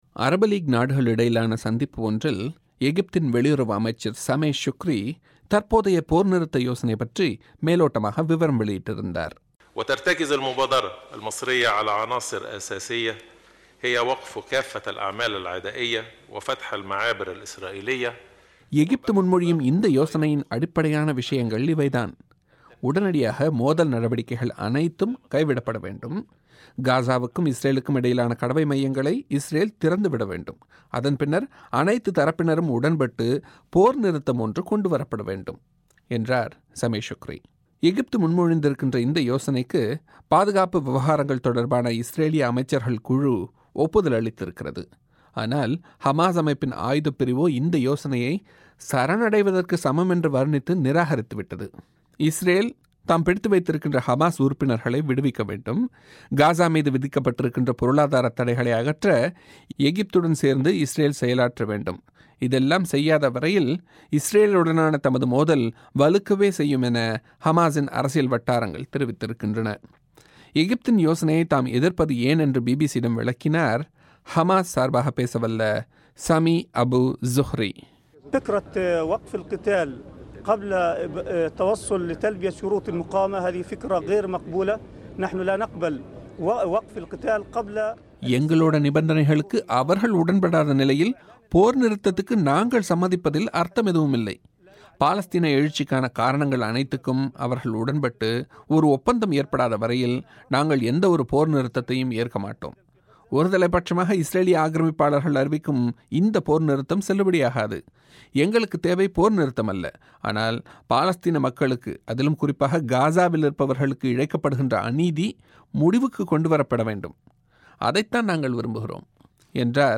இஸ்ரேல், ஹமாஸ் இடையிலான சண்டையை நிறுத்துவதற்கு எகிப்து முன்மொழிந்துள்ள யோசனையை இஸ்ரேலிய அமைச்சர்கள் அங்கீகரித்துள்ளனர். ஆனால் சரணடைவதற்கு சமம் என்று கூறி ஹமாஸ் அதை நிராகரித்துள்ளது. இது தொடர்பில் எகிப்து, இஸ்ரேல், ஹமாஸ், அமெரிக்கா ஆகிய தரப்புகளின் கருத்துகள் அடங்கிய ஒலிப் பெட்டகத்தை நேயர்கள் இங்கு கேட்கலாம்.